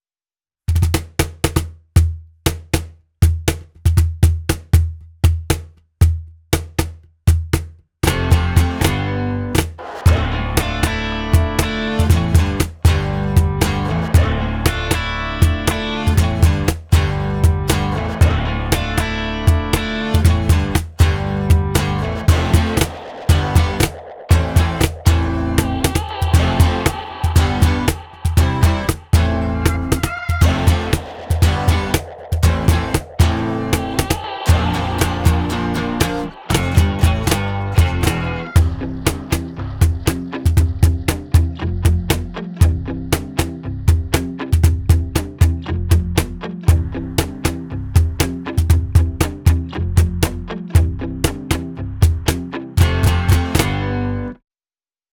MEINL Percussion Snarecraft Series Snare Cajon 100 - Heart Ash (SC100HA)
MEINL Snarecraft cajons combine articulate rhythmic punctuation with a warm tonal quality to give players a focused sound and a wide dynamic range.…